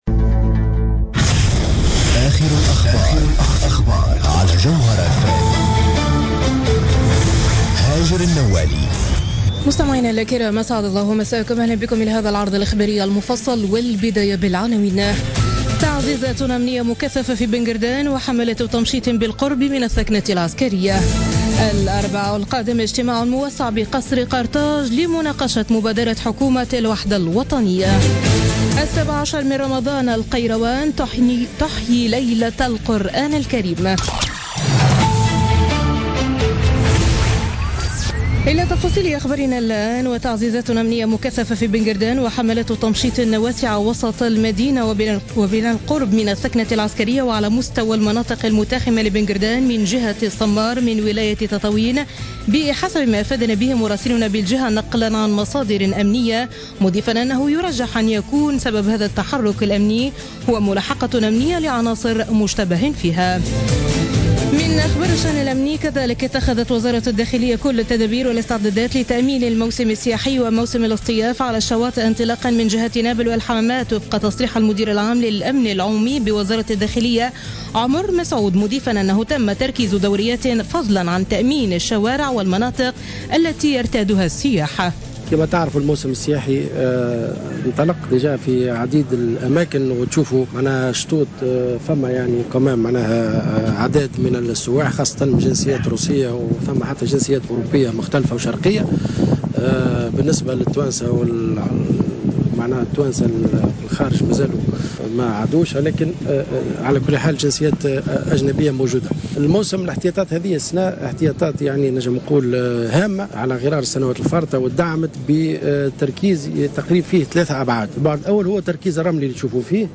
نشرة أخبار منتصف الليل ليوم الأحد 19 جوان 2016